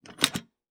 Part_Assembly_47.wav